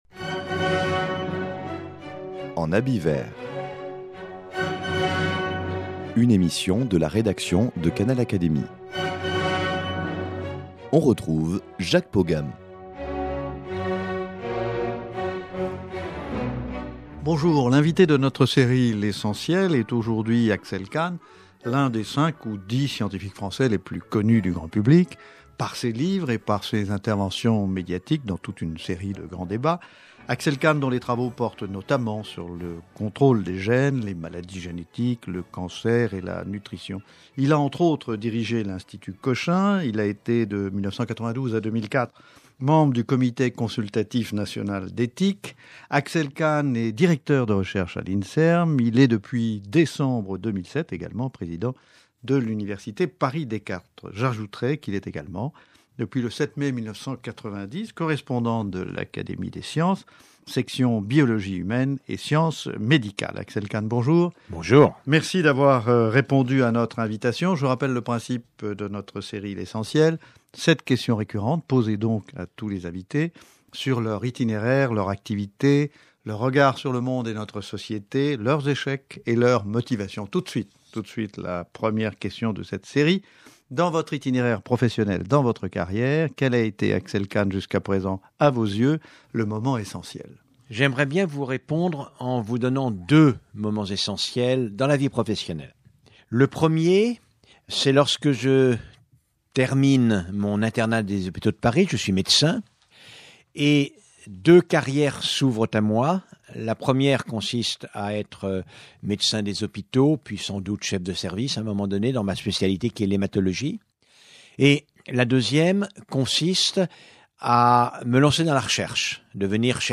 Un conseil de sagesse parmi d’autres qu’il confie dans cet entretien.